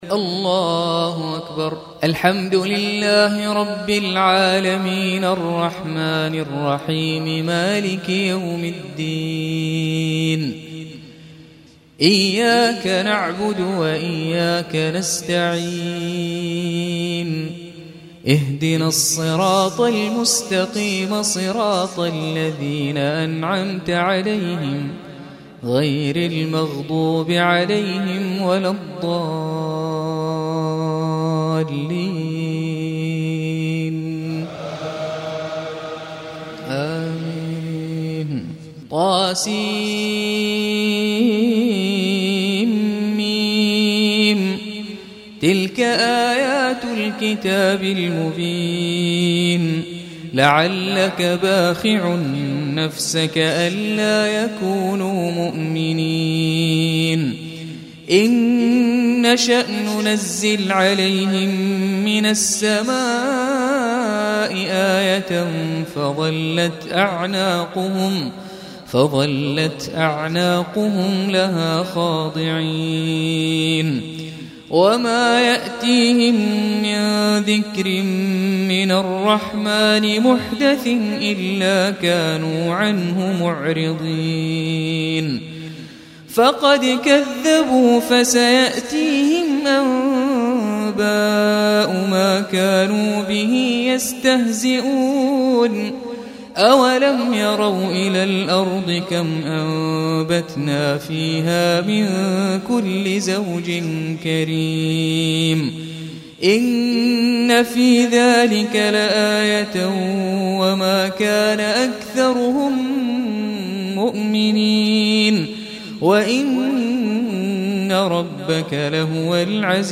اغاني ليالي رمضانية لعام 1435 هـ , البومات ليالي رمضانية لعام 1435 هـ ,تلاوات من صلاة التراويح والقيام,